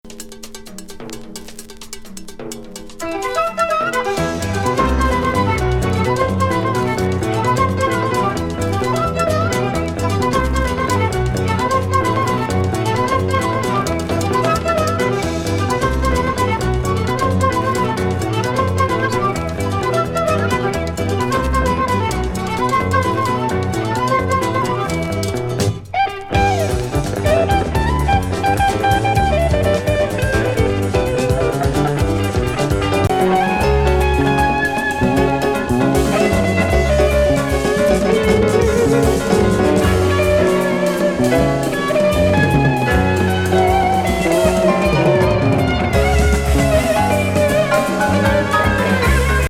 ヘビー・サイケ・ジャム